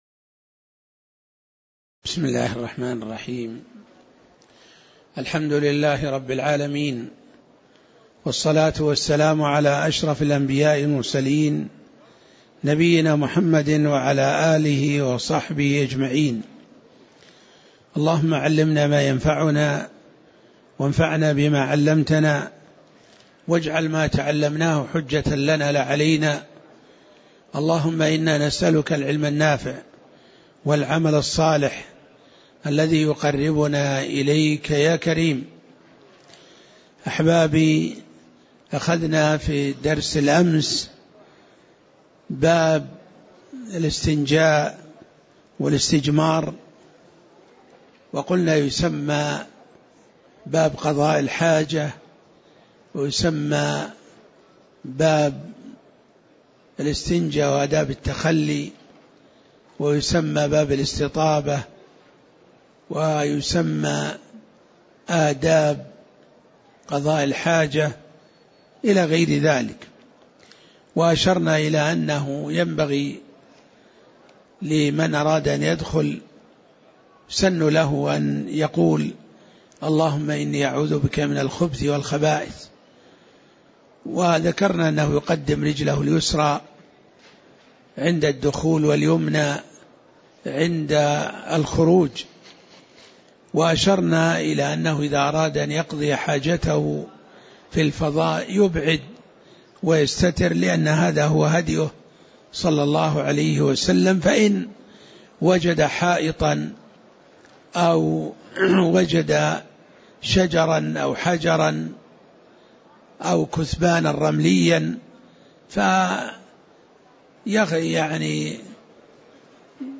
المكان: المسجد النبوي الشيخ: عبدالله بن محمد الطيار عبدالله بن محمد الطيار باب السواك وسنن الفطرة (04)